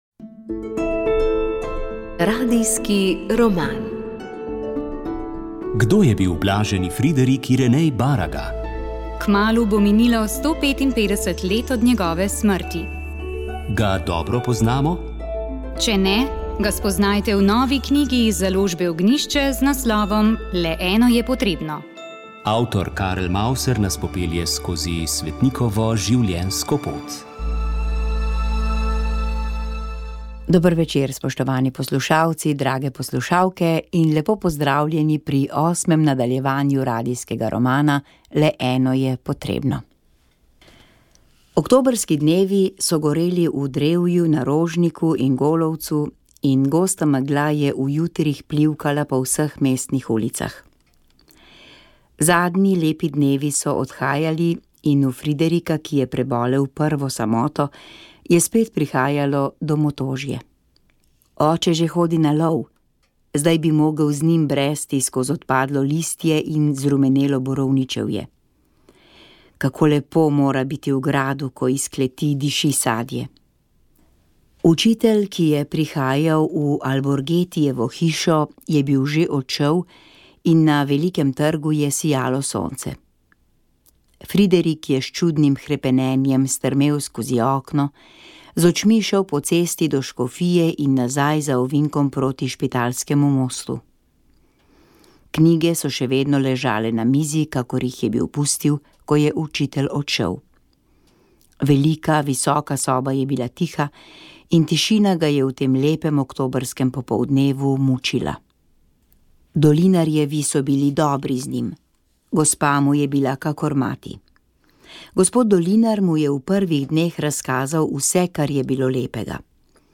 Radijski roman